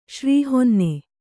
♪ śrī honne